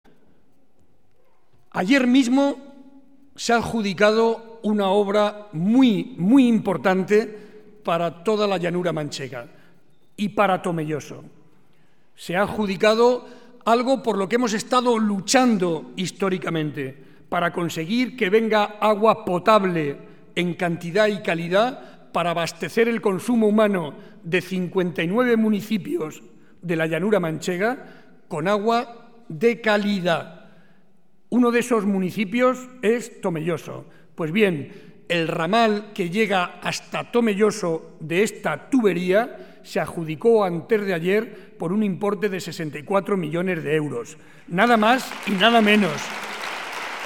Ante más de 1.500 vecinos de la localidad, en el Teatro Municipal, subrayó que Tomelloso se ha convertido en un nudo de comunicaciones en el corazón de La Mancha.